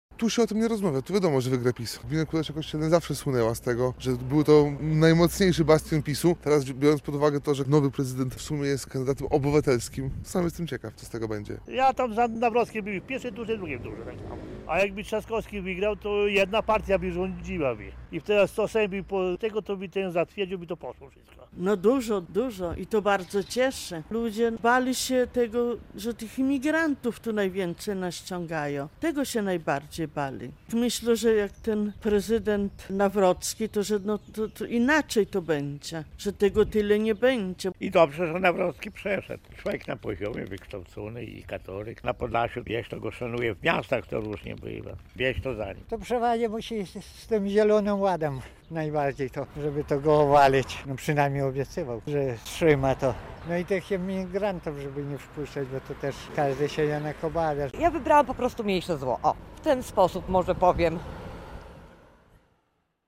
Mieszkańcy Kulesz Kościelnym mówią, dlaczego głosowali na Karola Nawrockiego